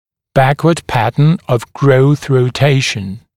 [‘bækwəd ‘pætn əv grəuθ rə’teɪʃn][‘бэкуэд ‘пэтн ов гроус рэ’тэйшн]тип роста с ротацией нижней челюти кзади